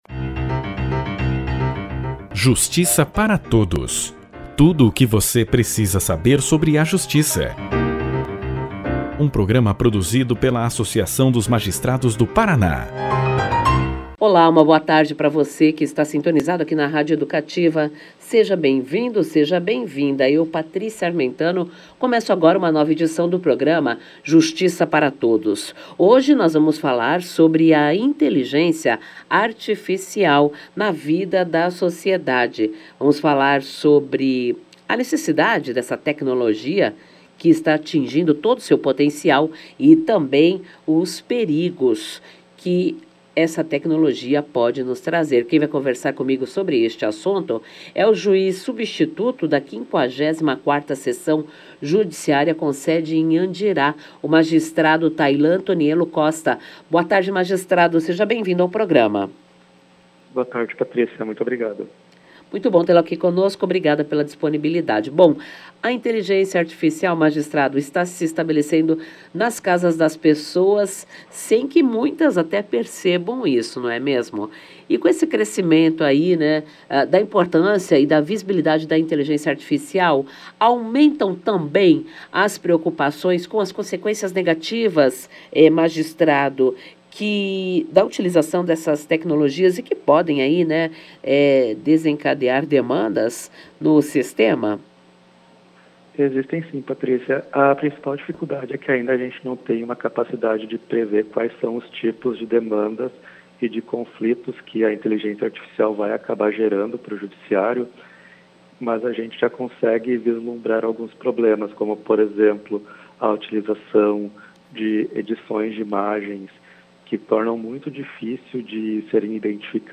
O programa de Rádio da AMAPAR, Justiça Para Todos, convidou o Juiz Substituto da 54ª Seção Judiciária com sede em Andirá, Tailan Tomiello Costa, para falar sobre os benefícios e os riscos do avanço da Inteligência Artificial.